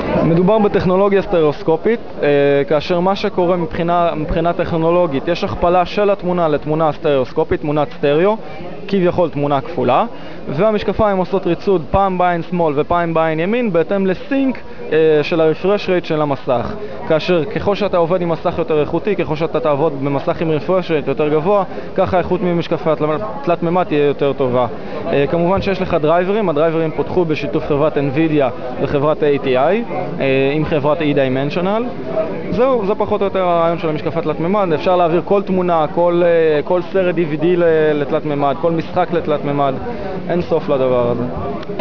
הסבר אודיו על הטכנולגיה.
3D_Glasses_Interview.wav